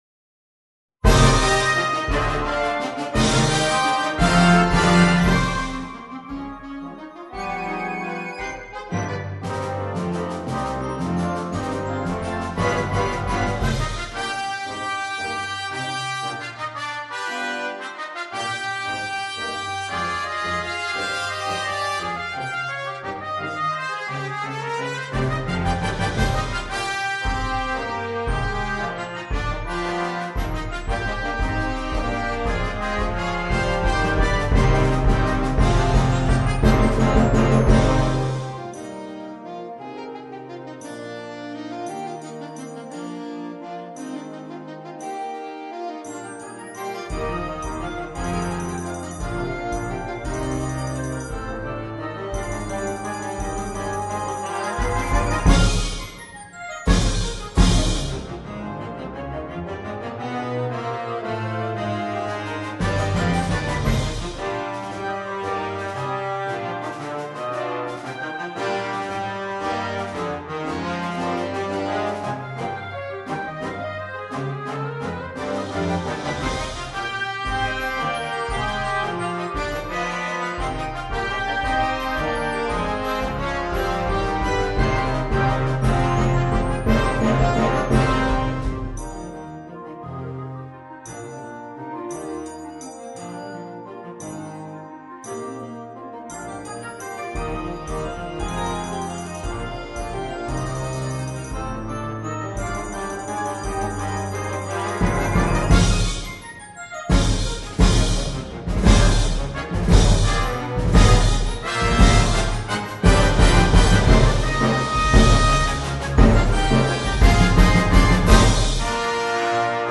GOLDEN MARCH
for banda